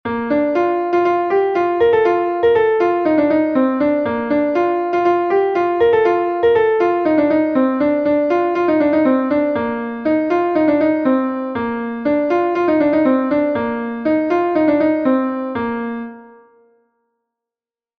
Gavotenn Er Gemene Bro-Bourled is a Gavotte from Brittany